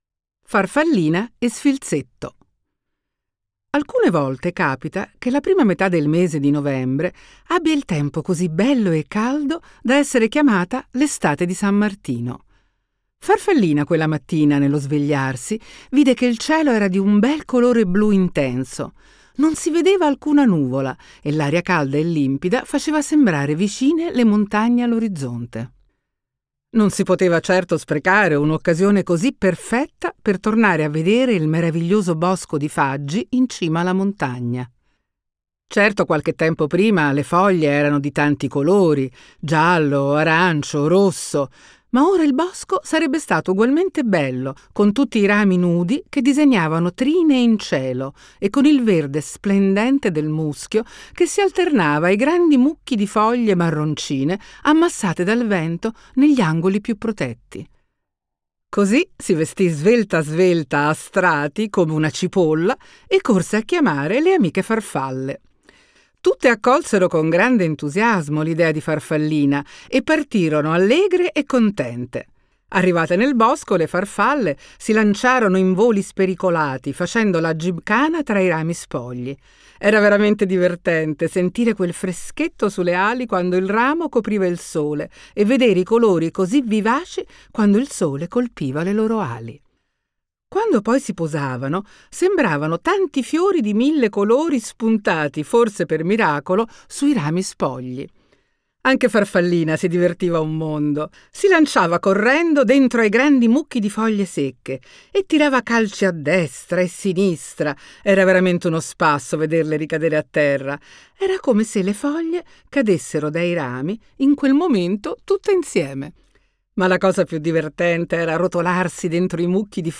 Voce di Serena Dandini